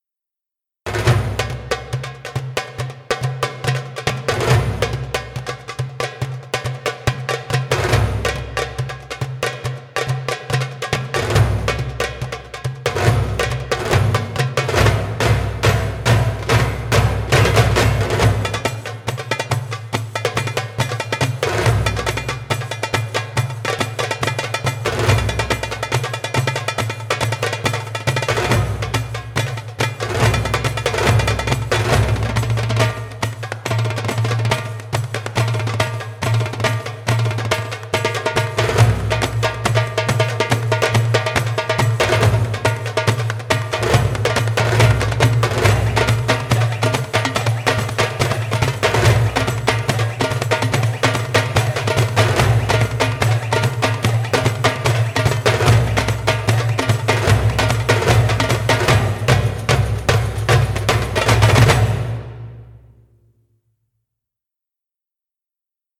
它适合用于制作电影配乐和中东风格的音乐。
- 三种不同的录音模式：单人、三人和六人合奏
- 多种不同大小的Darbuka乐器可供选择